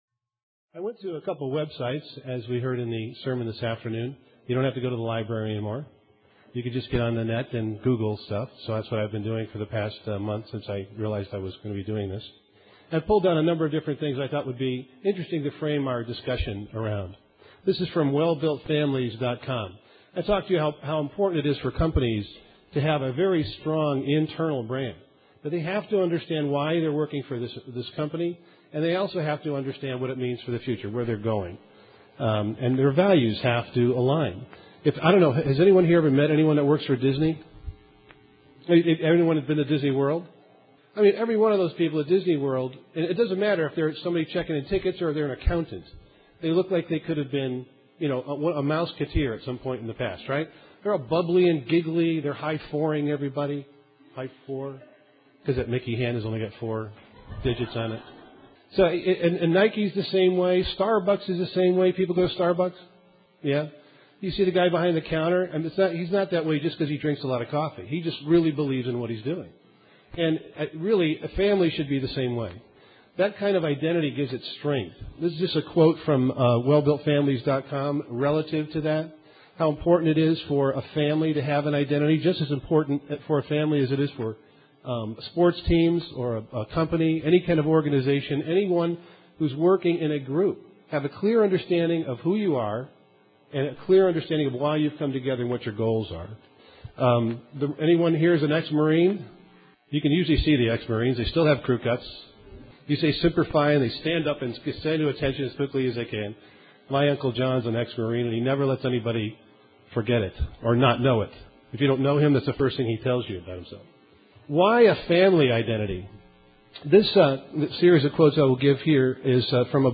This message was presented as part of the Twin Citie's Families For God Weekend, March 12-14, 2010